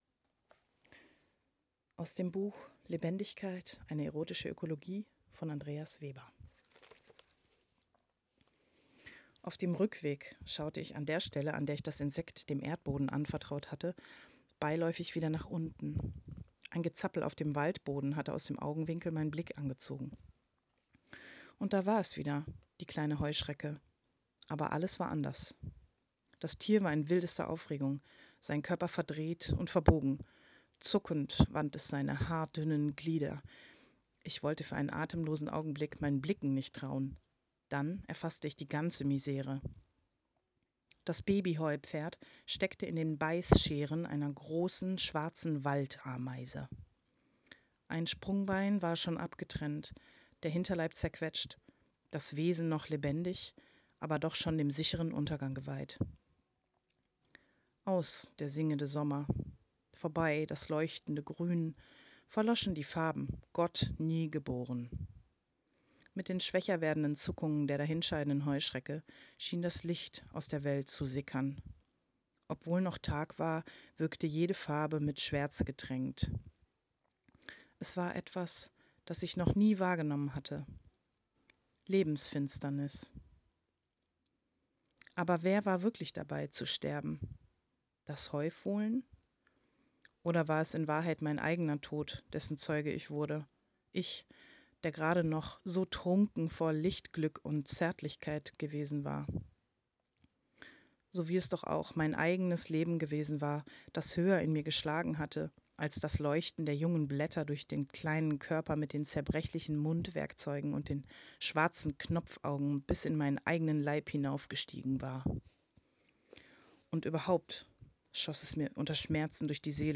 Heute möchte ich einen kleinen Ausschnitt aus dem Buch, das ich gerade lese und das mich sehr berührt und erschüttert, als Audio mit euch teilen.